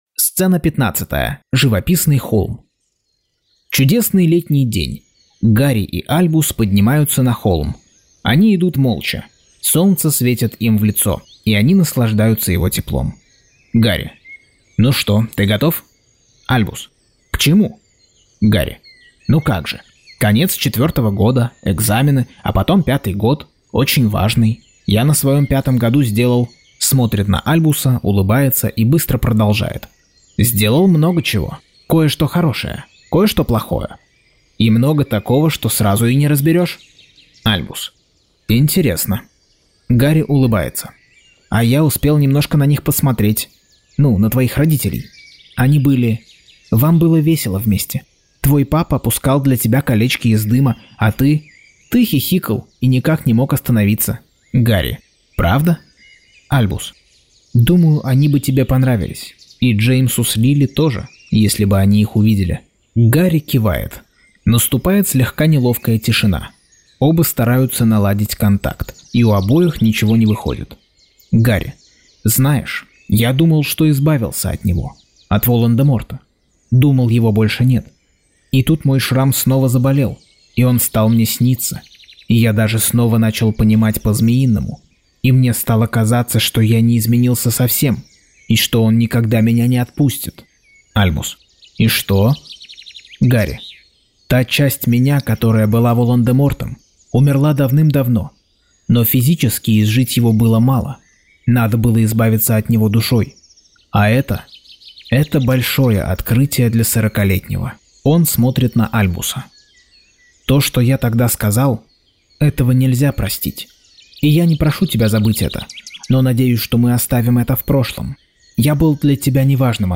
Аудиокнига Гарри Поттер и проклятое дитя. Часть 64.